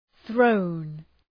Προφορά
{ɵrəʋn}
throne.mp3